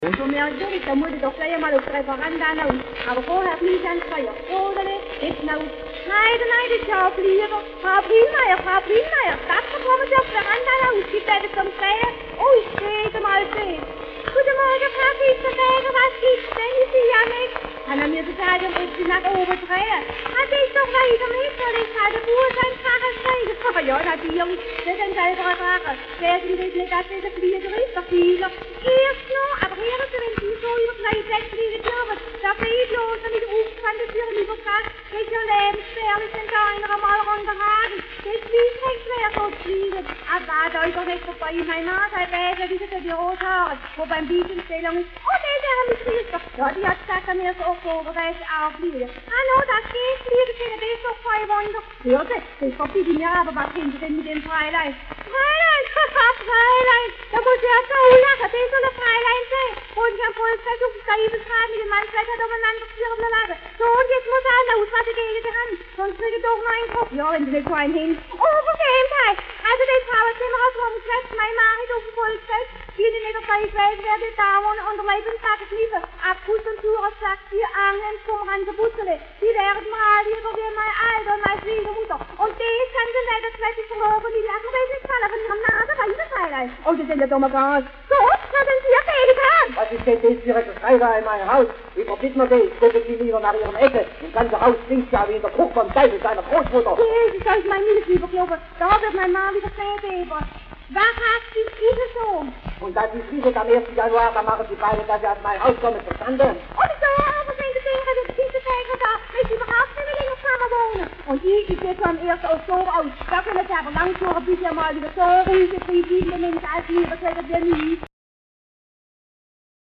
Schellackplattensammlung